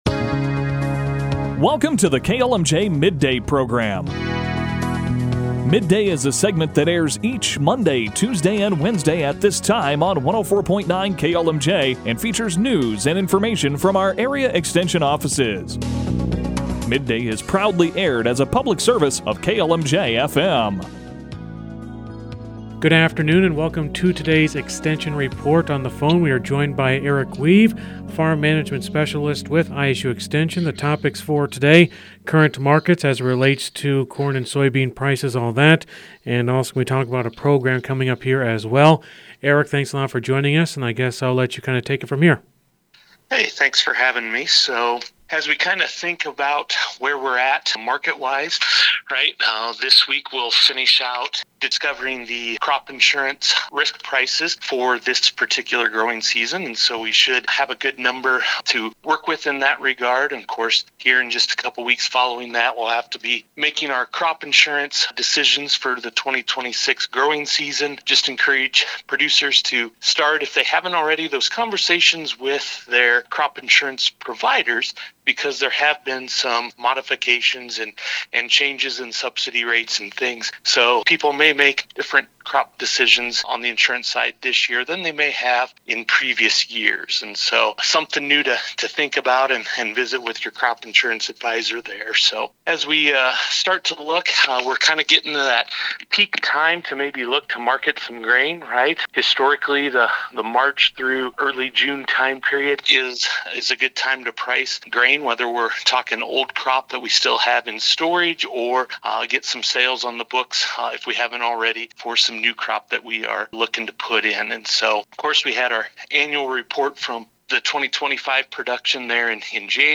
Full interview below